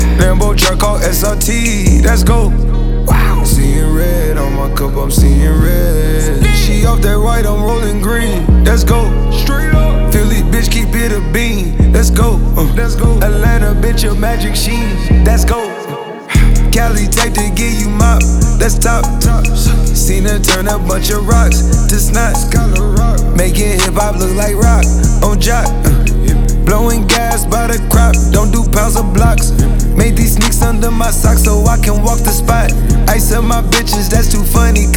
Hip-Hop Rap